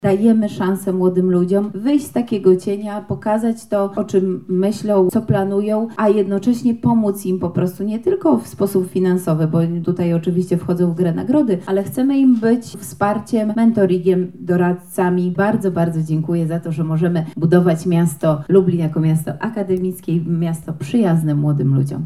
– mówi Beata Stepaniuk-Kuśmierzak, Zastępczyni Prezydenta Miasta Lublin ds. Kultury, Sportu i Partycypacji.